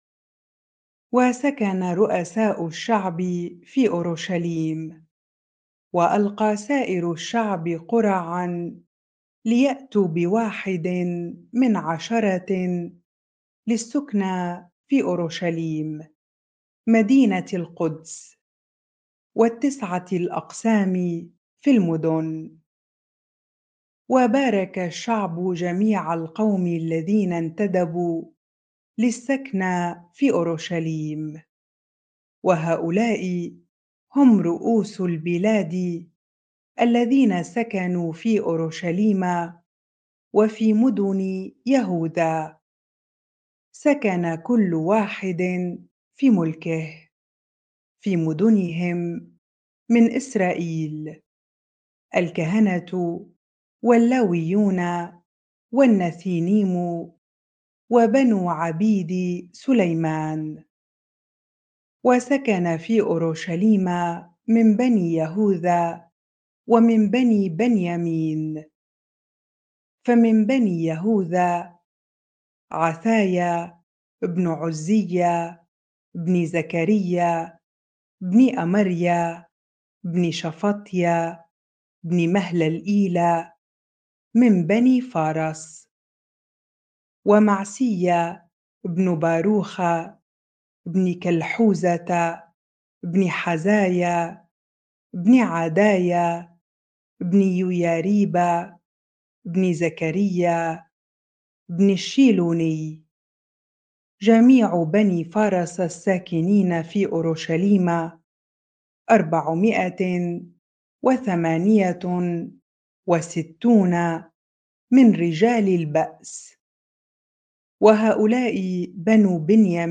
bible-reading-Nehemiah 11 ar